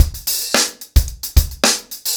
TupidCow-110BPM.59.wav